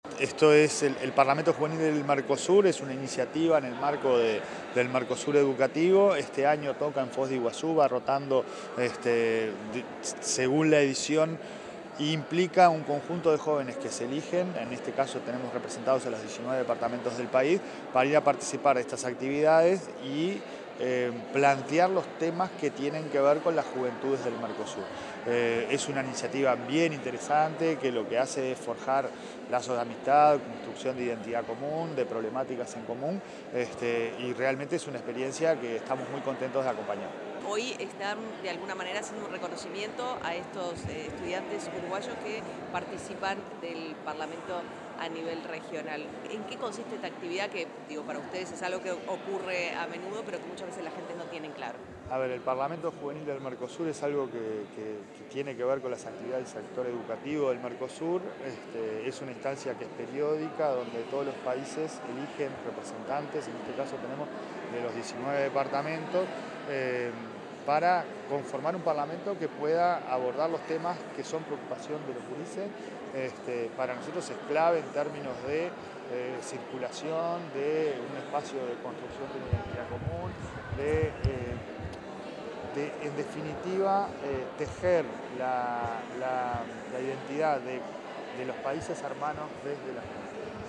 Declaraciones del presidente de la ANEP, Pablo Caggiani
Declaraciones del presidente de la ANEP, Pablo Caggiani 24/07/2025 Compartir Facebook X Copiar enlace WhatsApp LinkedIn Declaraciones del presidente de Administración Nacional del Educación Pública (ANEP), Pablo Caggiani, en la ceremonia de reconocimiento a la delegación de jóvenes que asistirá al Parlamento Juvenil del Mercosur.